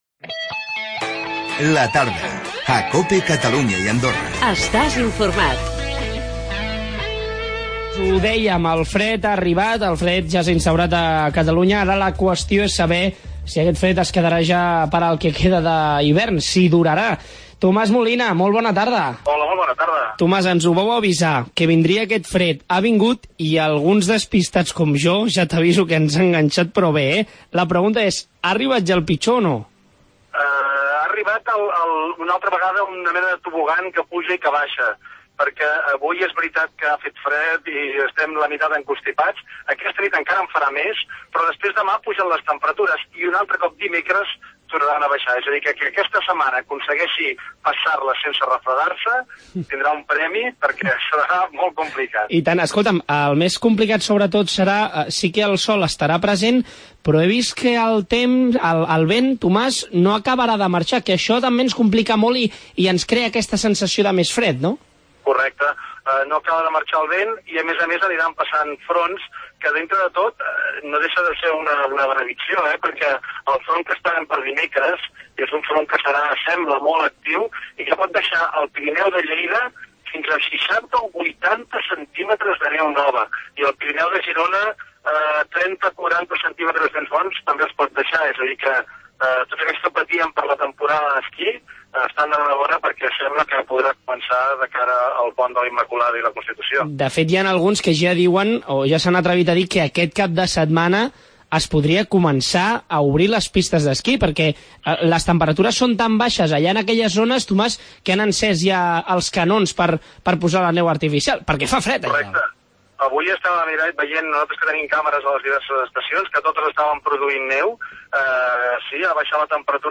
El fred ja està aquí, i per saber quant temps durarà hem parlat amb el meteoròleg Tomàs Molina